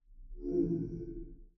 creatures_ghost_hit.ogg